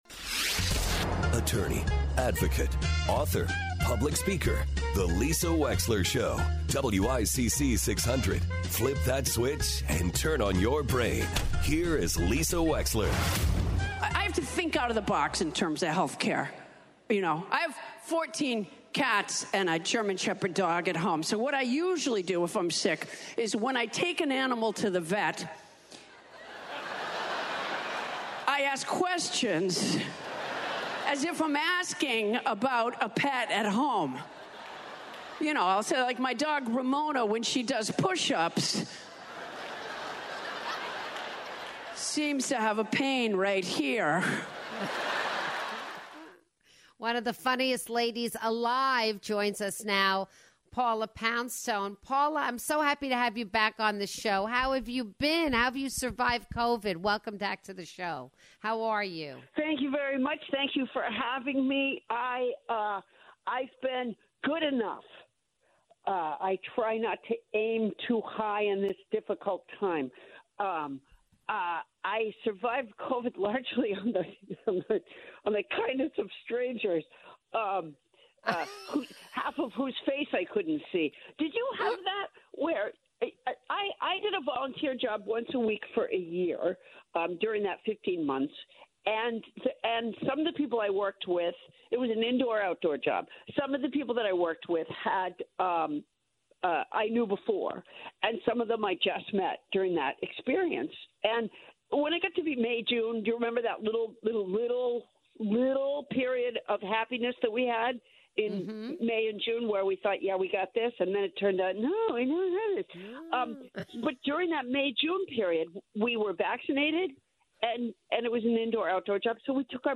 Comedian Paula Poundstone joins the show to discuss life during the pandemic and to preview her upcoming show in New Haven.